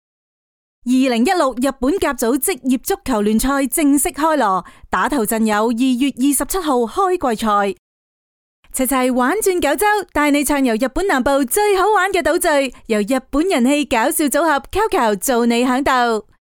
Female
My voice is warm, friendly, clear, gentle, enthusiastic, firm, and sweet, with the versatility to perform across e‑learning, narration, commercial work, character voice acting, and more.
Radio Commercials